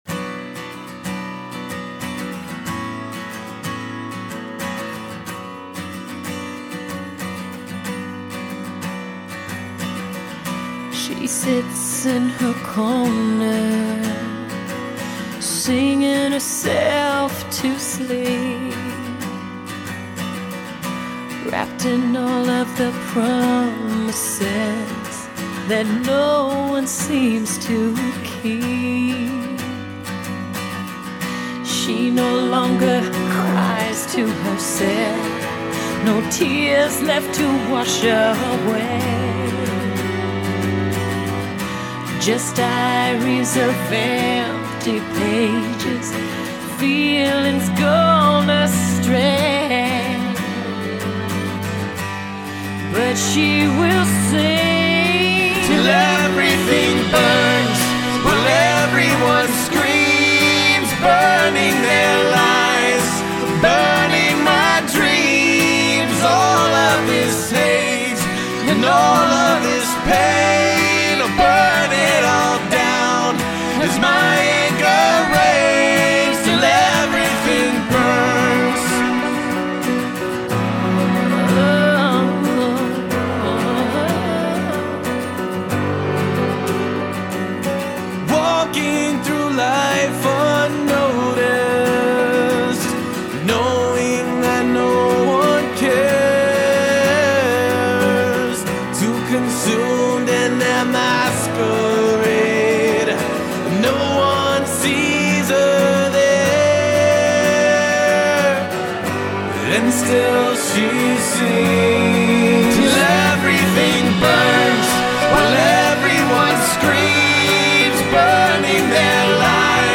Файл в обменнике2 Myзыкa->Зарубежный рок
Жанр: Pop/Rock; Битрэйт